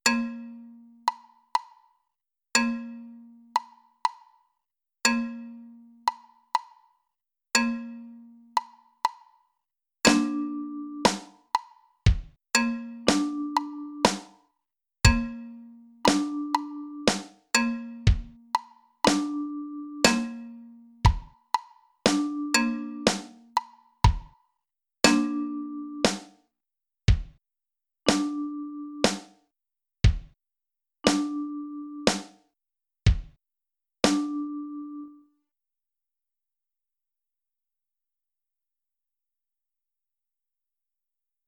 MIDI audio of the 5 cycle vs. the 6